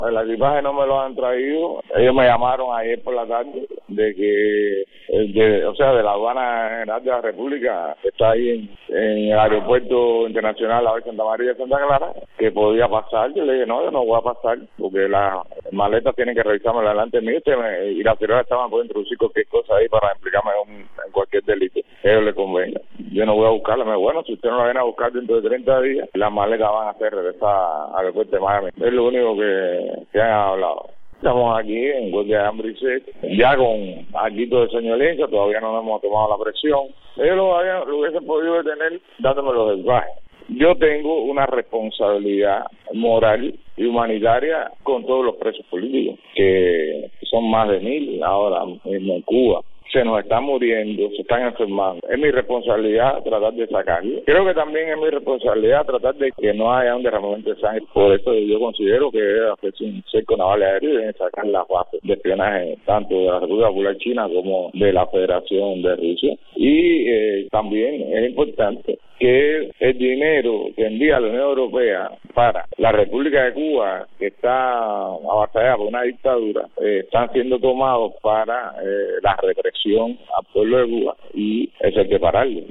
En su segunda jornada en huelga de hambre, desde su vivienda, en la ciudad de Santa Clara, el opositor cubano Guillermo Fariñas dijo a Martí Noticias que aún no se le ha devuelto el equipaje que traía al regresar a la isla el pasado viernes, y explicó las demandas de su protesta.